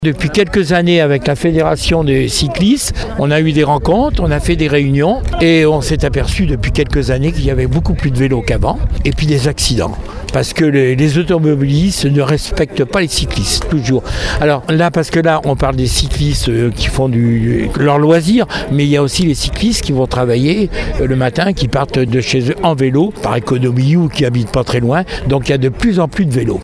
Gérard Pons, vice-président du Département en charge de la mobilité et des infrastructures :